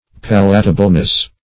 Palatableness \Pal"a*ta*ble*ness\, n.